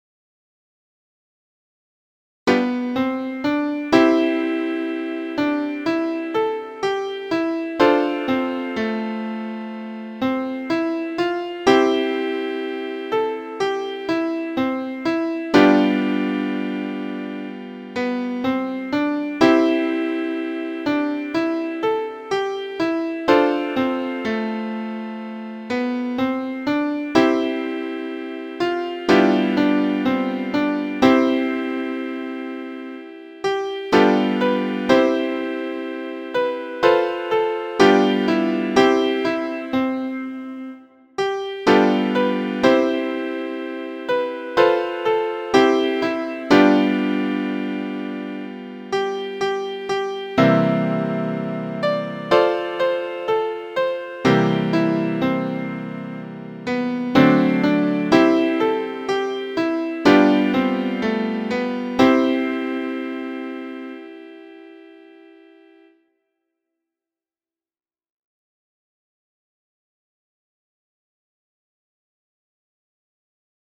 Syncopated rhythms, descending tonic triad and arpeggio, and three intervals of a minor third.
Origin: Old Irish Air – Words: Fred E Weatherly
Key: C Major
Time: 4/4
Form: ABCD